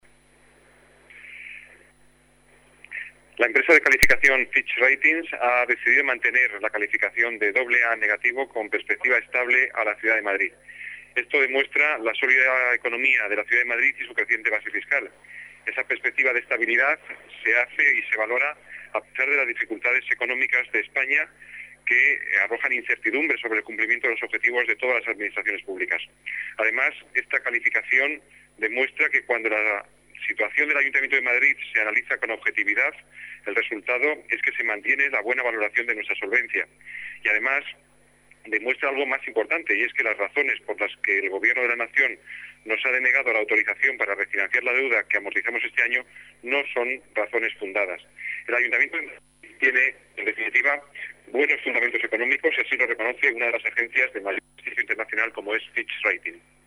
Nueva ventana:Declaraciones del delegado de Economía y Hacienda, Juan Bravo